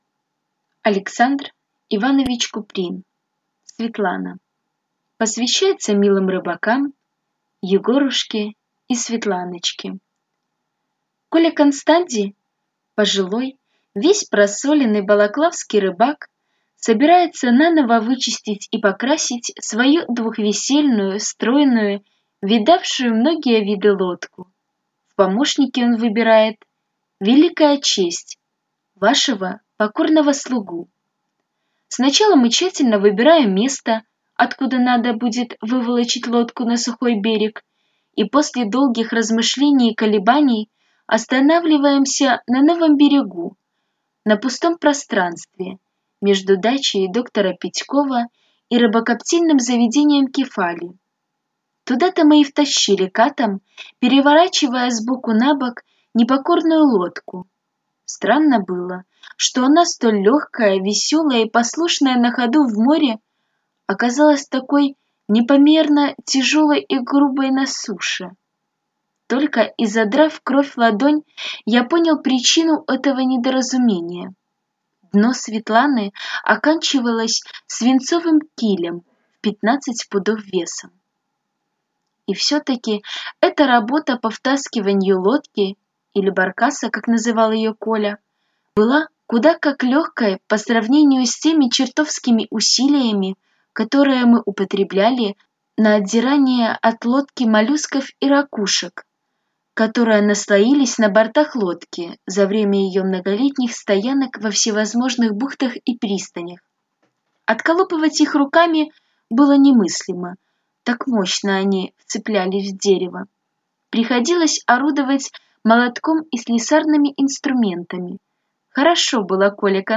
Аудиокнига «Светлана» | Библиотека аудиокниг